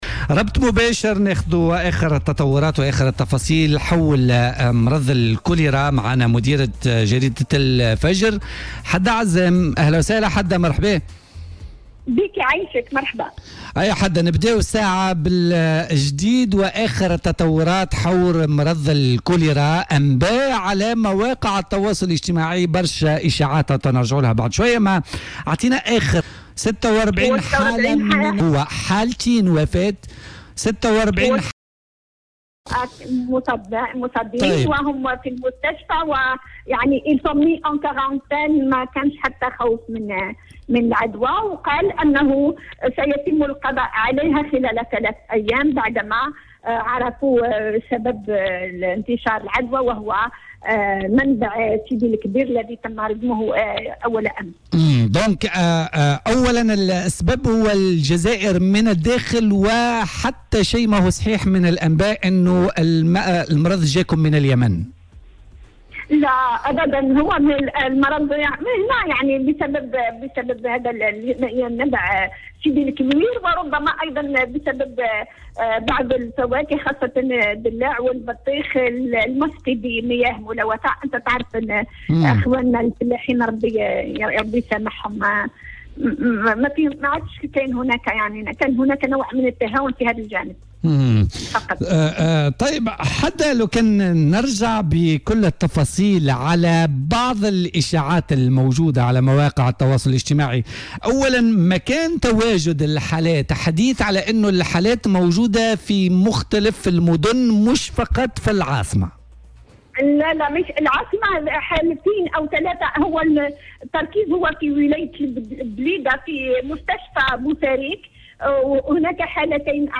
ورجحت في مداخلة هاتفية من الجزائر في "بوليتيكا" أن يكون أيضا ري زراعات البطيخ و"الدلاع" بمياه ملوثة (مياه الصرف الصحي) وراء انتشار هذه الجرثومة التي طالت 46 حالة فيما أسفرت عن وفاة شخصين مشيرة إلى انه تم تسجيل هذه الحالات بالأساس في منطقة البليدة.